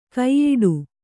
♪ kaiyiḍu